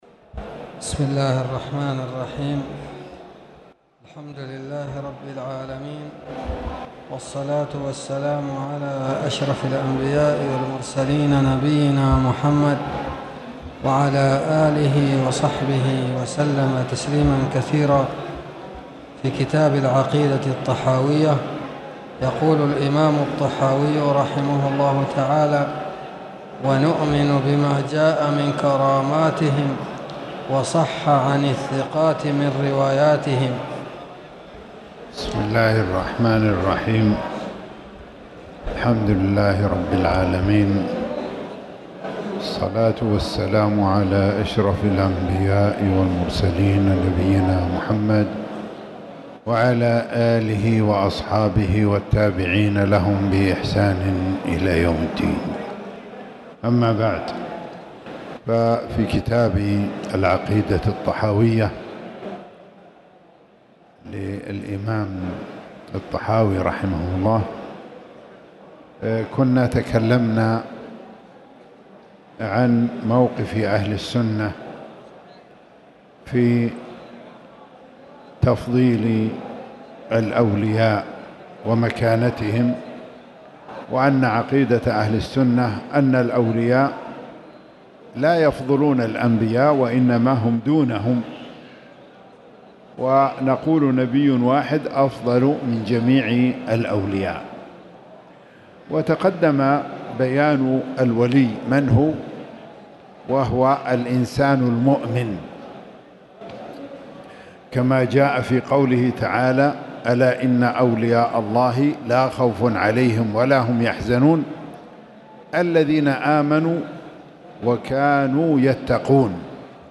تاريخ النشر ١١ ربيع الثاني ١٤٣٨ هـ المكان: المسجد الحرام الشيخ